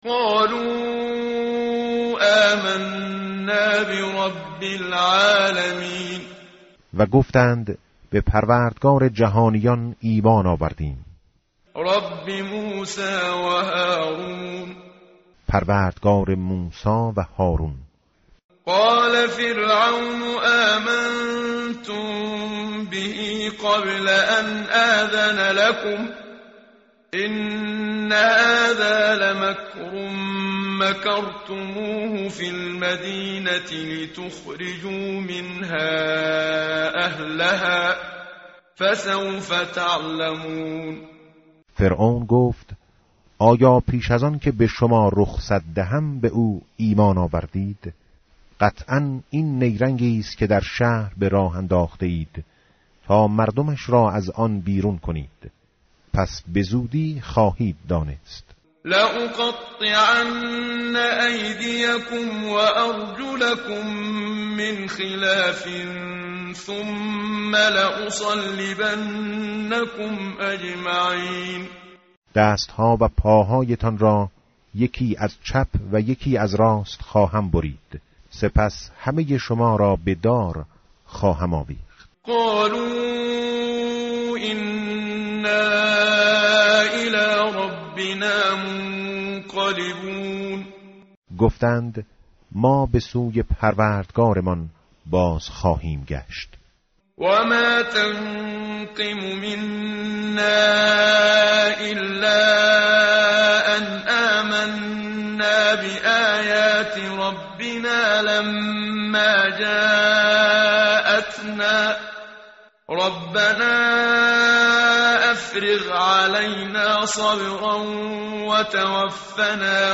متن قرآن همراه باتلاوت قرآن و ترجمه
tartil_menshavi va tarjome_Page_165.mp3